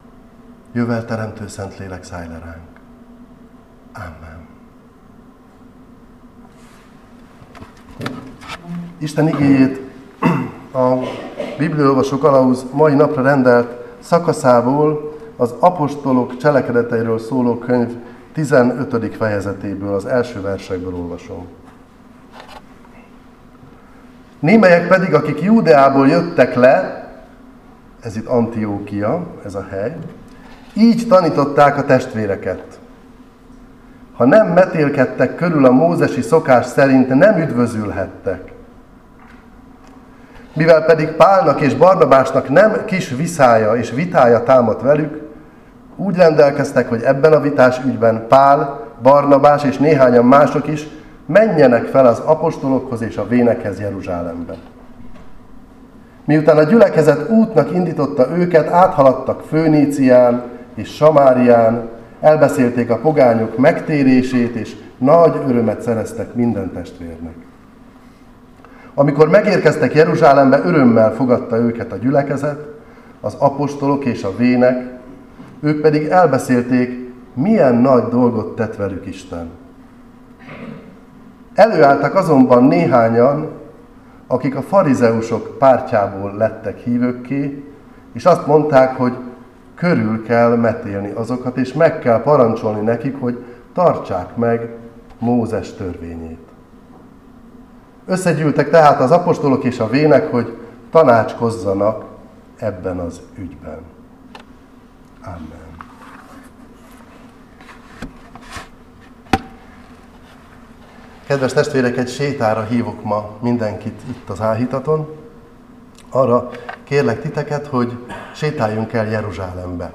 Áhítat, 2025. november 11.
egyetemi lelkész